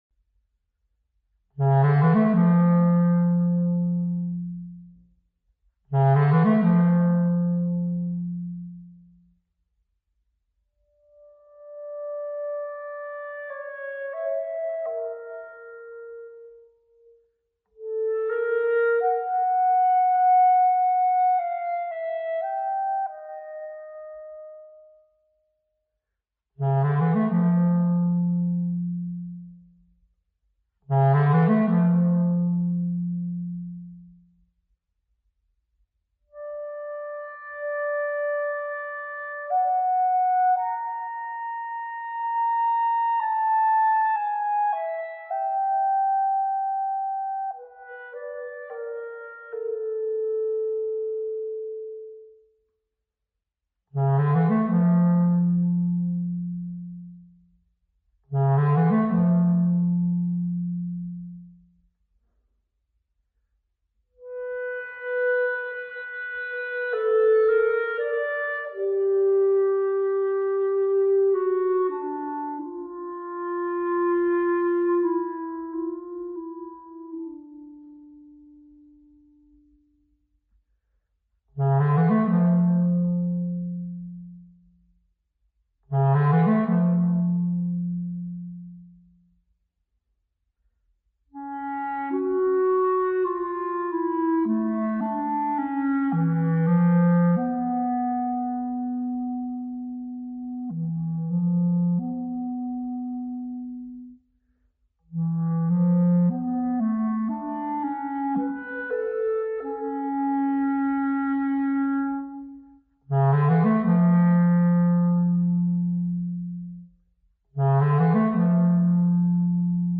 Per clarinetto solo
clarinet